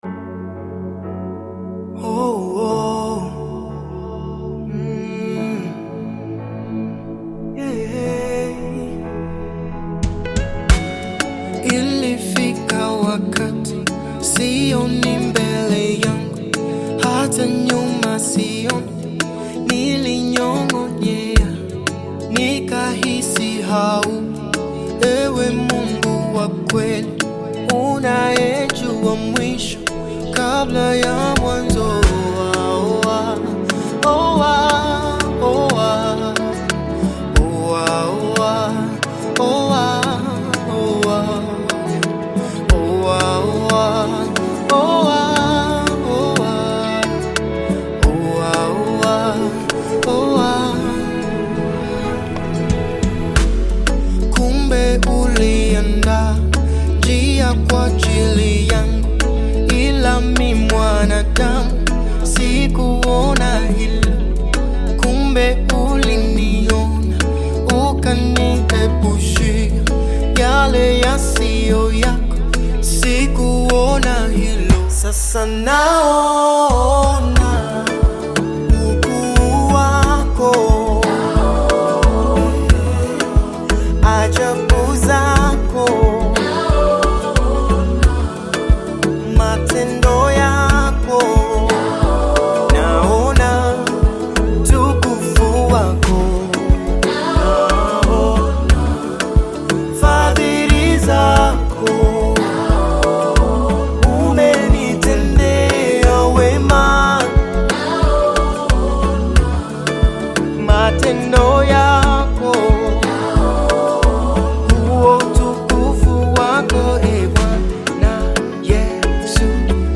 Gospel music track